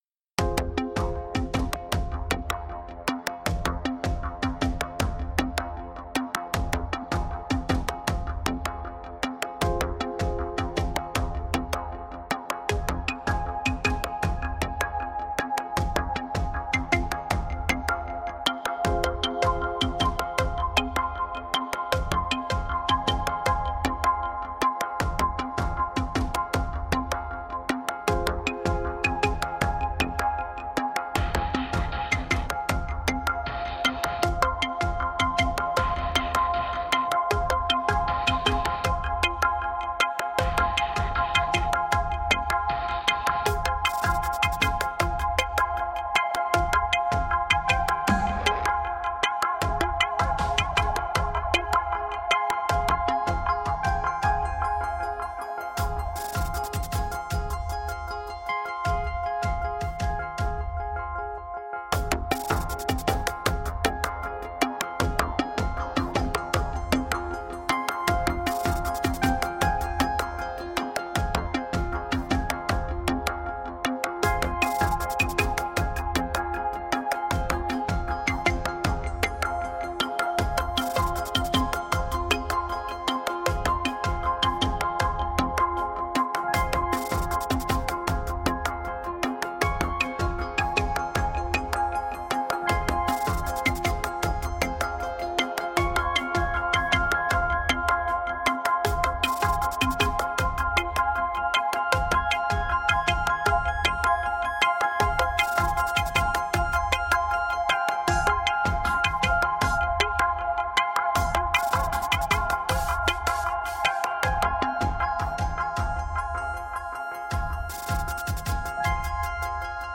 Музыка для подкастов на радио